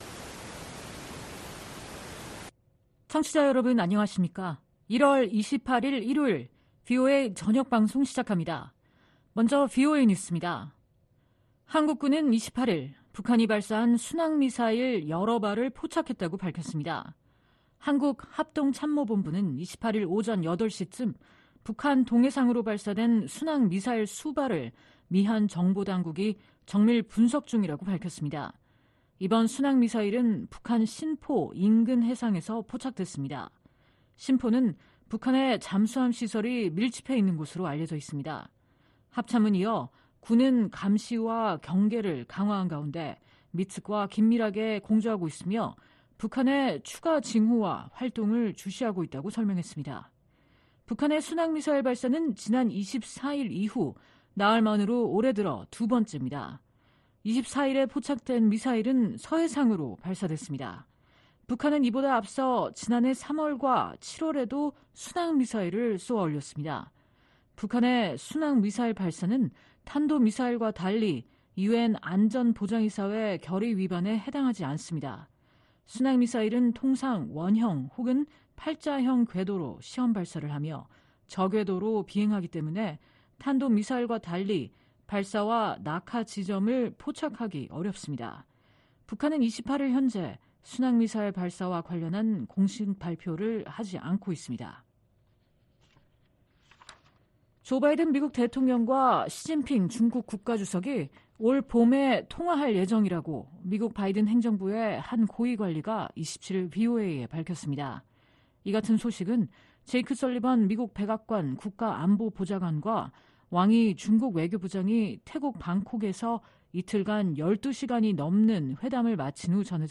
VOA 한국어 방송의 일요일 오후 프로그램 1부입니다. 한반도 시간 오후 8:00 부터 9:00 까지 방송됩니다.